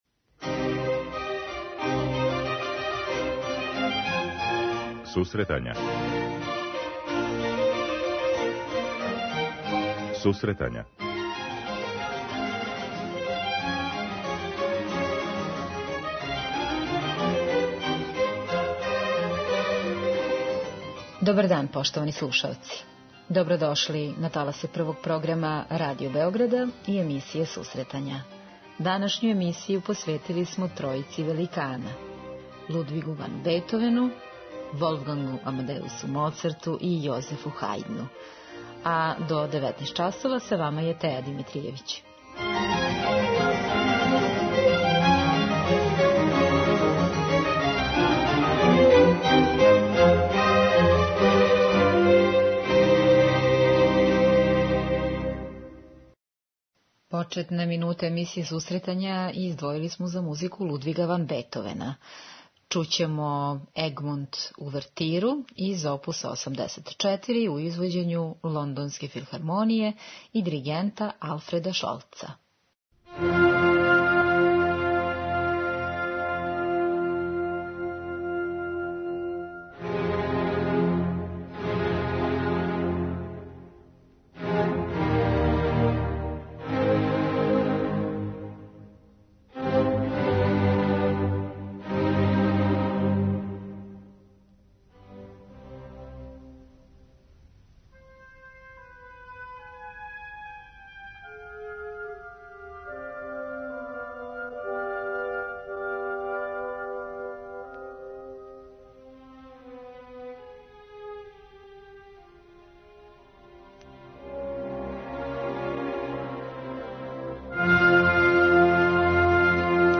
Данашњу емисију посвећујемо најзначајнијим уметничким фигурама класицизма- Хајдну, Моцарту и Бетовену и њиховим симфонијским, солистичким и камерним делима.